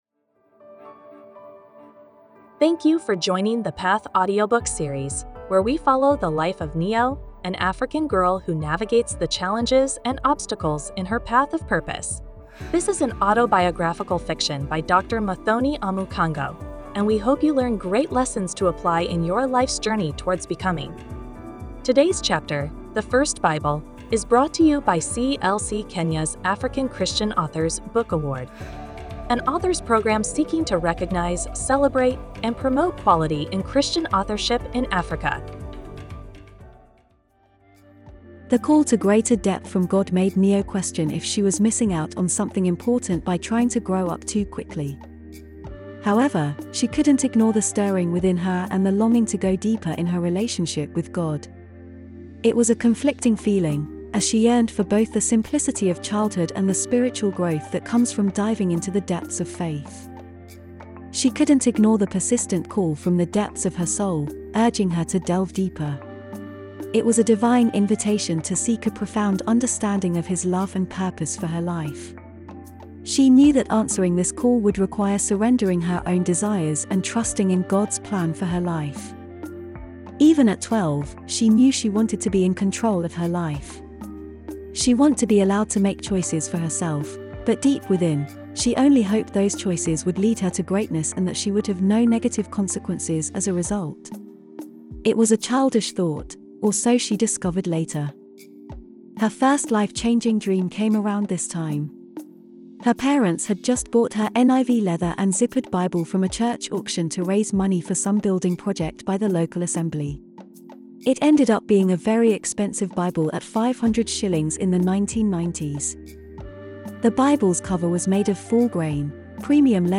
Thank you for joining The Path audiobook series, where we follow the life of Neo, an African girl who navigates the challenges and obstacles in her path of purpose.